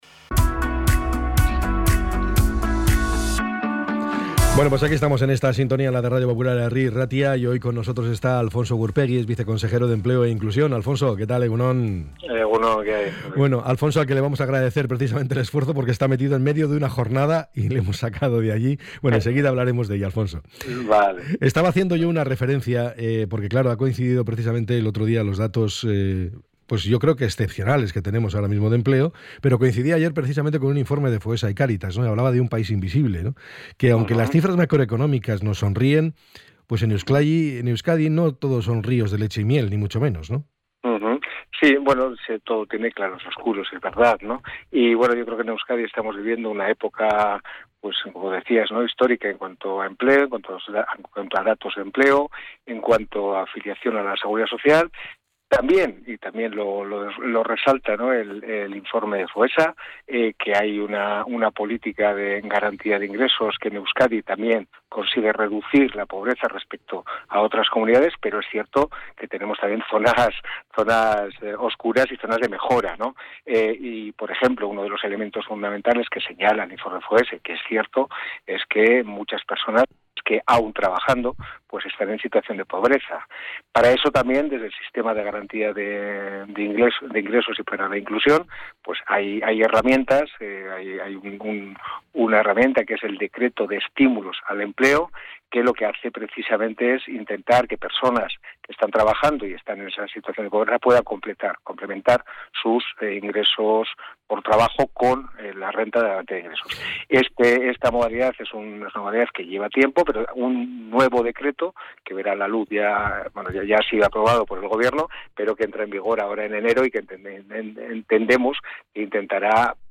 ENTREV.-ALFONSO-GURPEGI.mp3